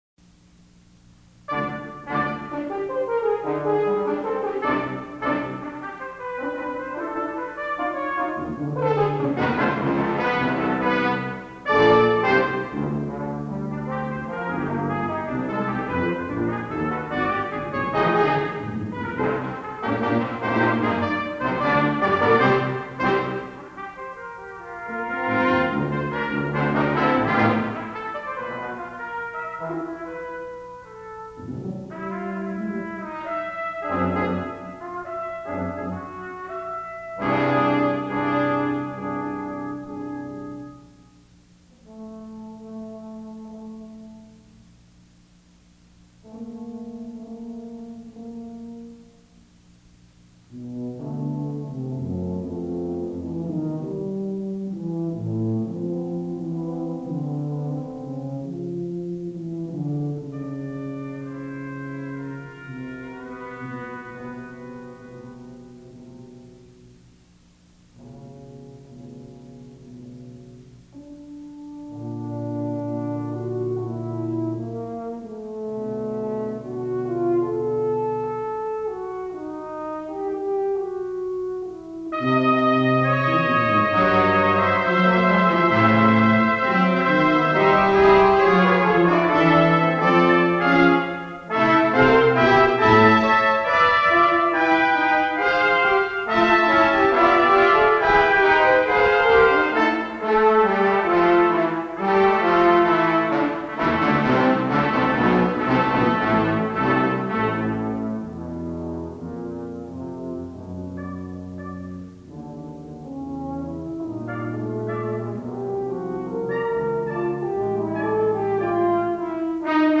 Bb Trumpet 1
Bb Trumpet 2
F Horn
Trombone
Tuba